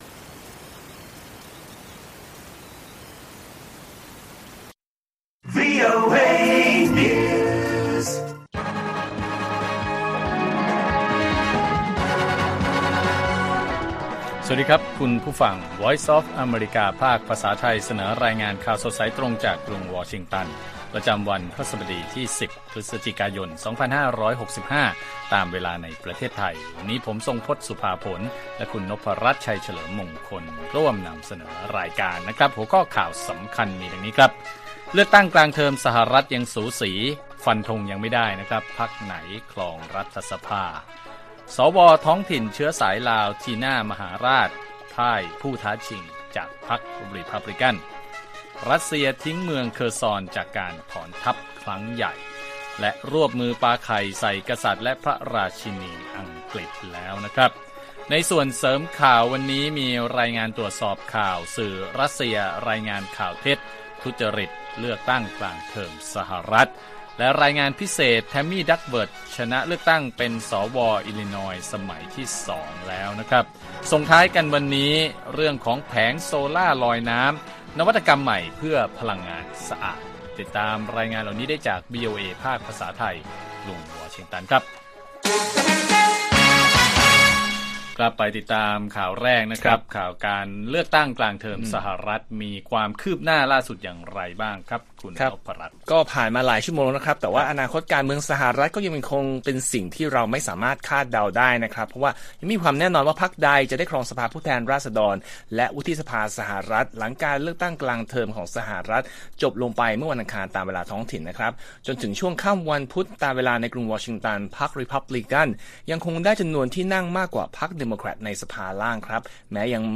ข่าวสดสายตรงจากวีโอเอไทย 8:30–9:00 น. 10 พ.ย. 2565